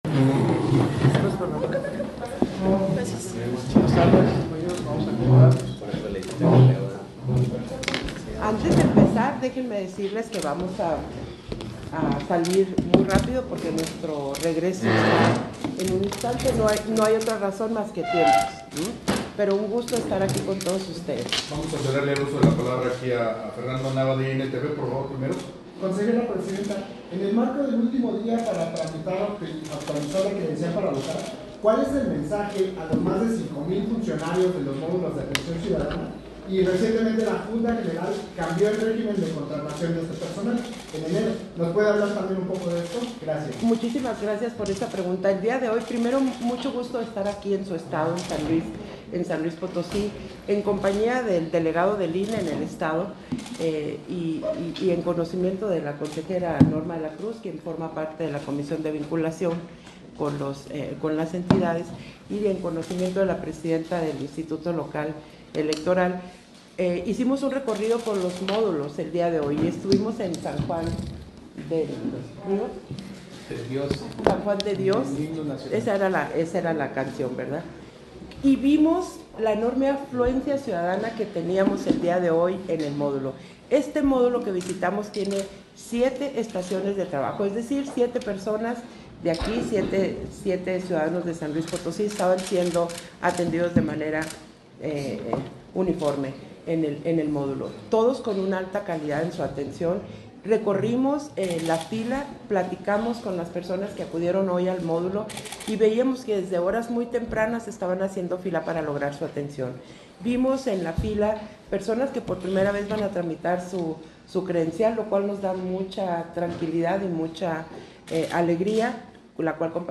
Versión estenográfica de la conferencia de prensa que ofreció la Consejera Presidenta, Guadalupe Taddei, al termino de la reunión con el gobernador de San Luis Potosí